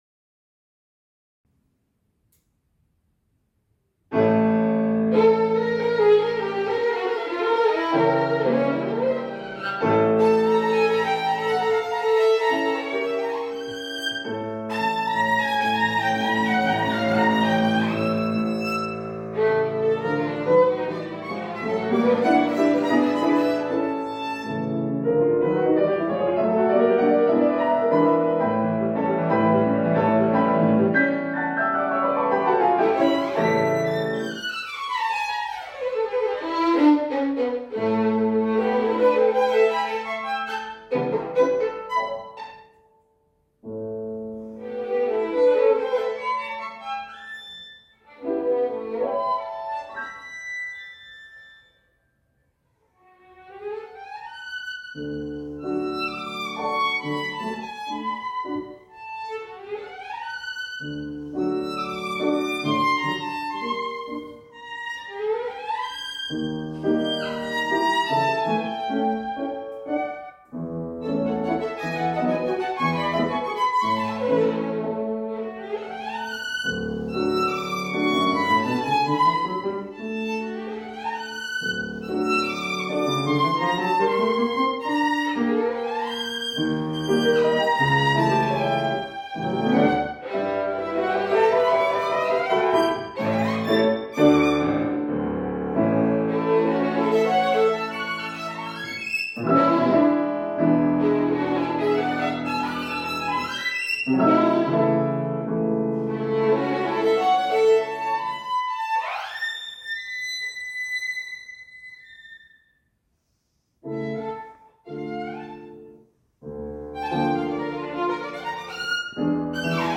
fortepian
skrzypce) w koncercie kameralnym "Classical Spring Concert", który odbył się w Mozarthaus Vienna (Wiedeń, Austria).
Wydarzenie zgromadziło tłumy słuchaczy, którzy nagrodzili artystki wielkimi brawami.